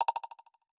End Call4.wav